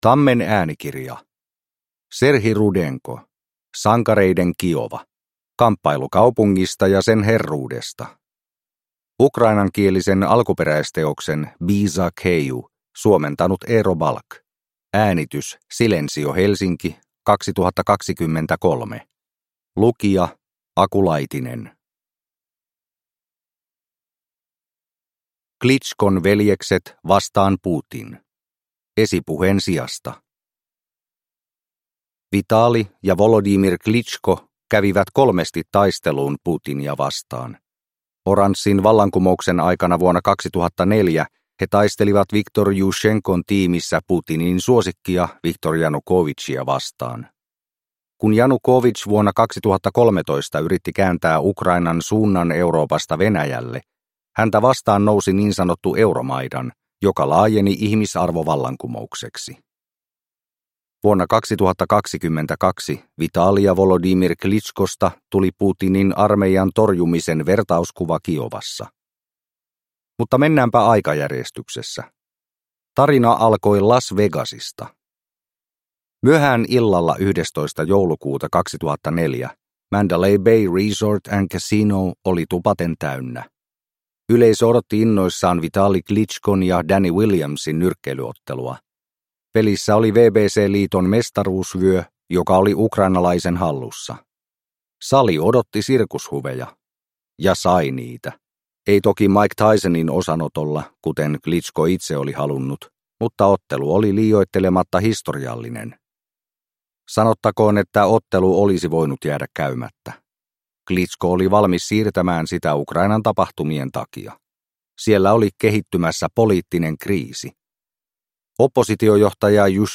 Sankareiden Kiova – Ljudbok – Laddas ner